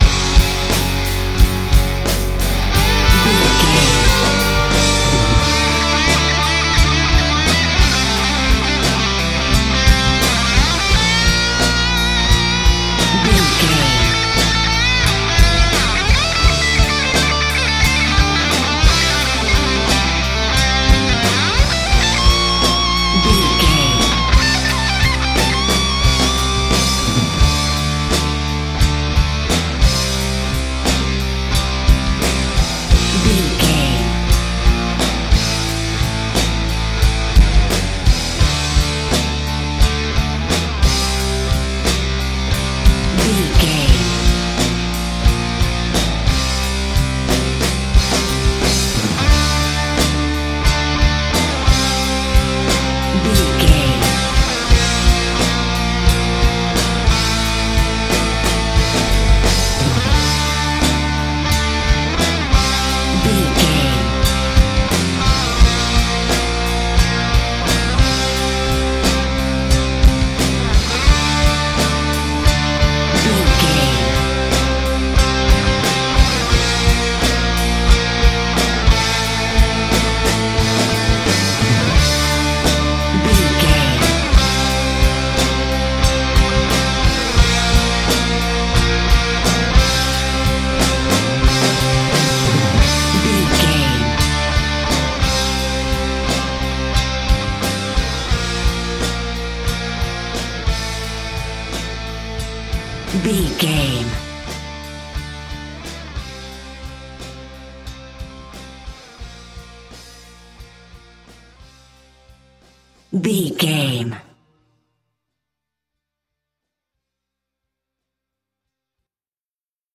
Aeolian/Minor
Slow
drums
electric guitar
bass guitar
pop rock
hard rock
lead guitar
aggressive
energetic
intense
powerful
nu metal
alternative metal